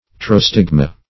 Search Result for " pterostigma" : The Collaborative International Dictionary of English v.0.48: Pterostigma \Pter`o*stig"ma\, n.; pl.